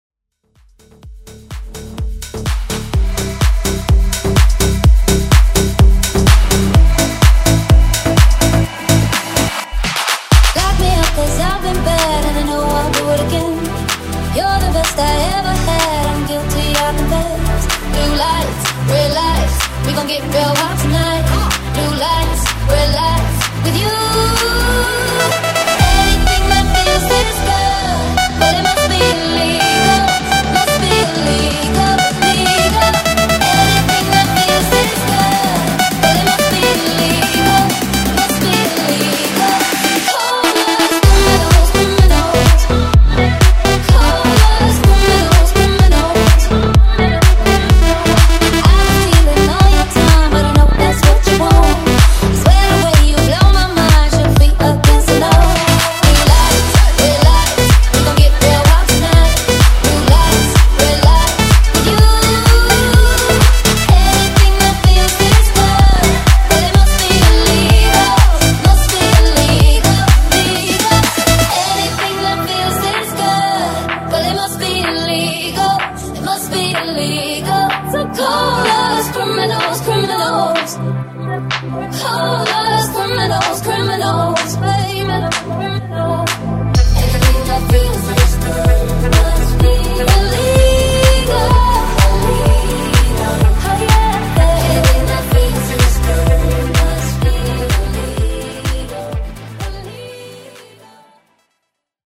Genres: FUTURE HOUSE , MASHUPS , TOP40
Clean BPM: 126 Time